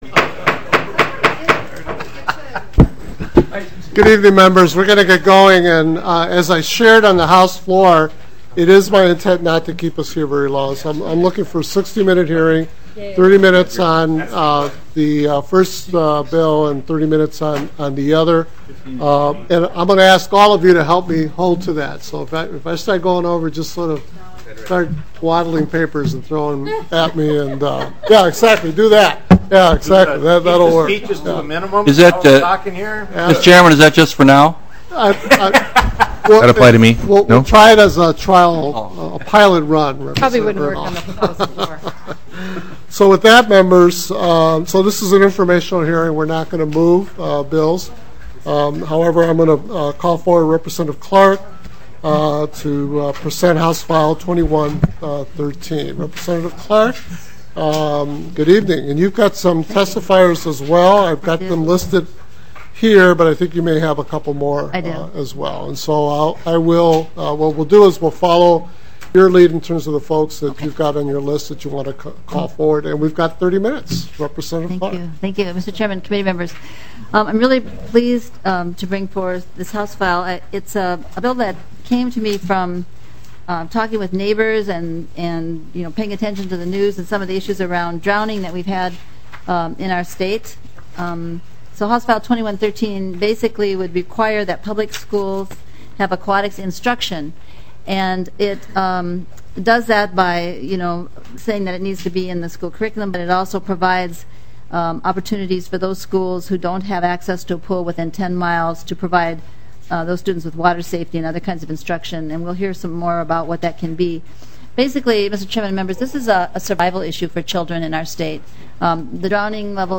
02:43 - Gavel. 03:26 - HF2113 (Clark) Aquatics instruction required in public schools. 49:00 - HF3045 (Mariani) Teaching Board directed to enter into teacher licensure interstate reciprocity agreement.